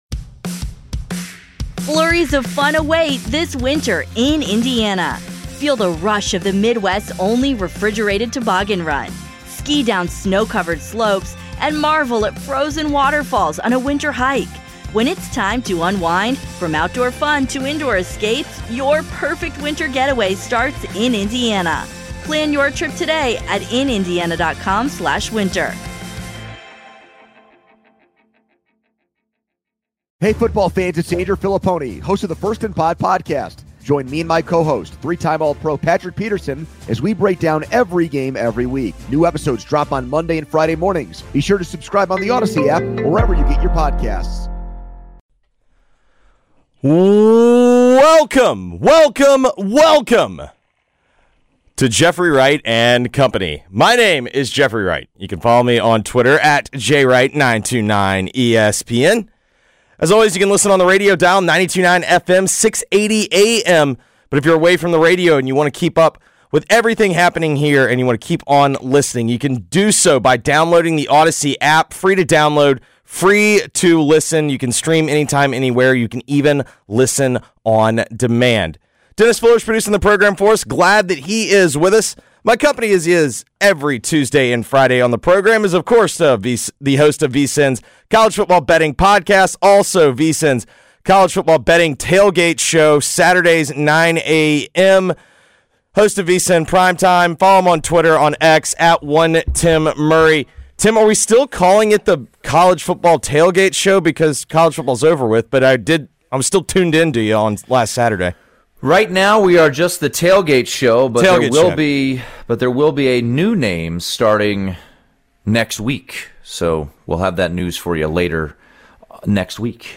live weekdays 2-4PM and always on the FREE Audacy App!